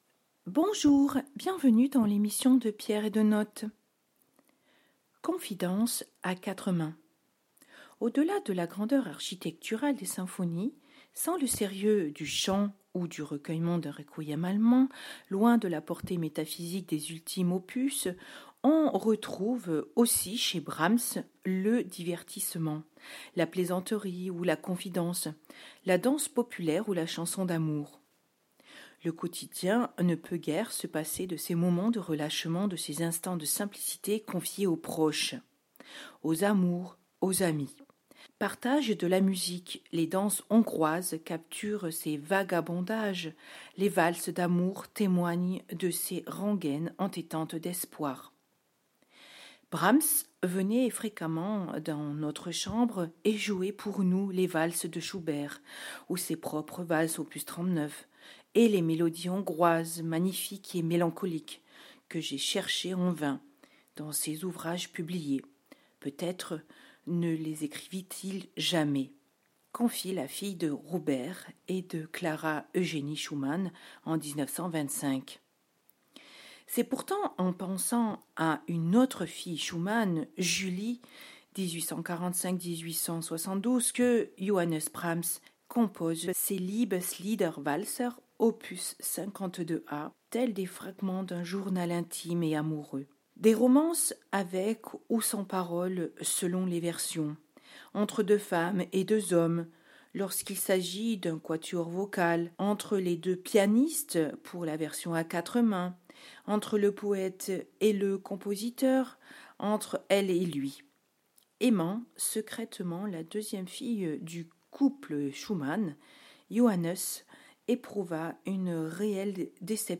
dpdn-brahms_liebeslieder-walzer_a_quatre_mains.mp3